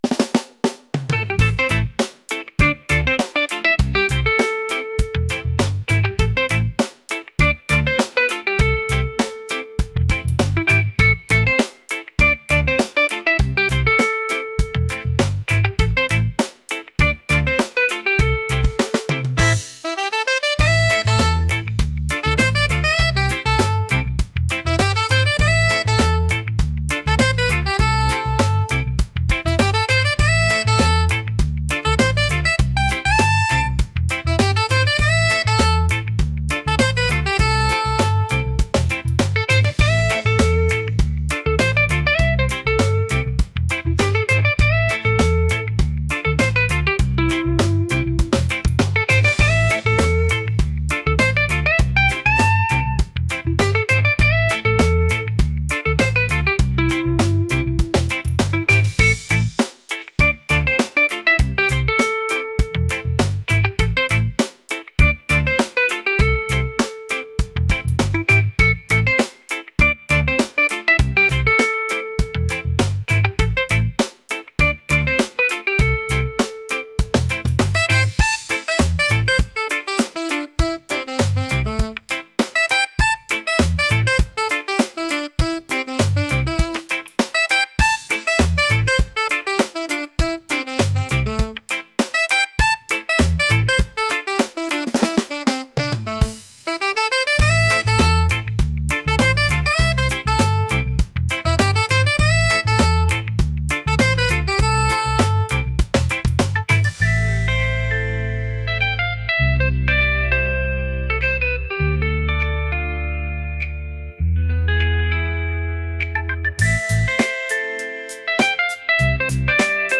upbeat | reggae | funk